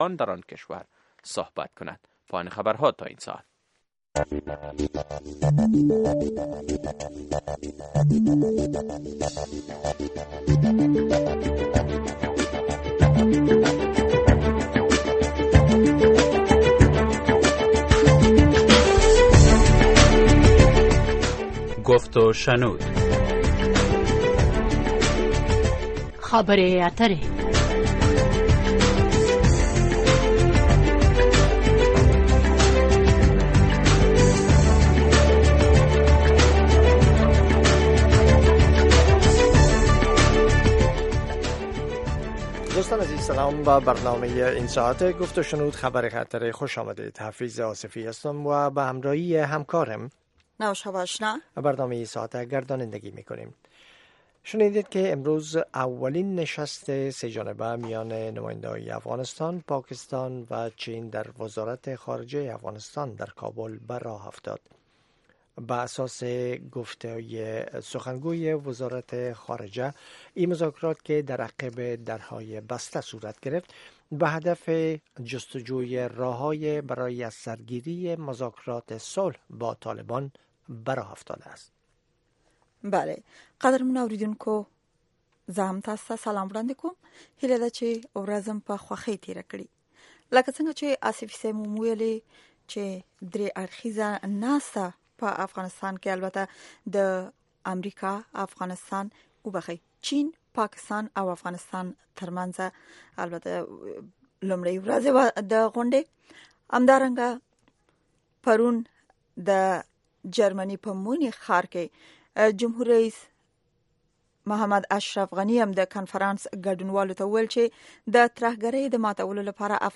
گفت و شنود - خبرې اترې، بحث رادیویی در ساعت ۸ شب به وقت افغانستان به زبان های دری و پشتو است. در این برنامه، موضوعات مهم خبری هفته با حضور تحلیلگران و مقام های حکومت افغانستان بحث می شود.